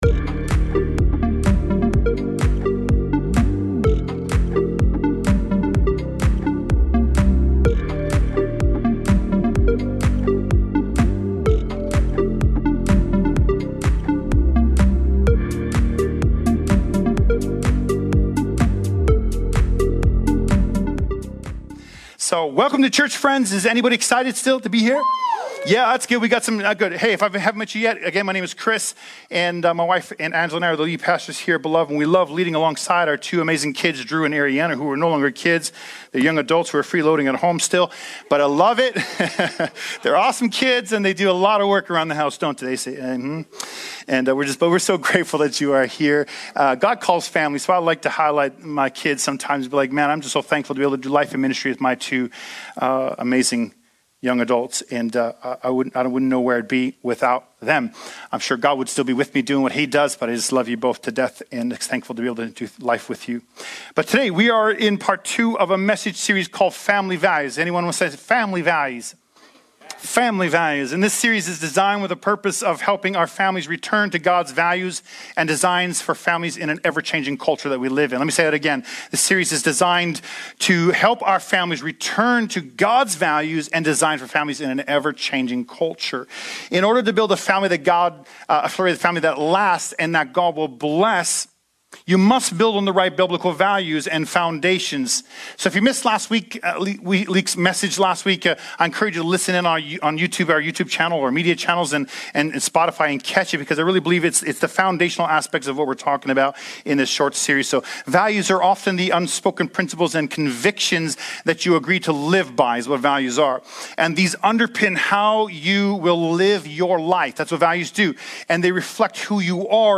Sermons | Beloved City Church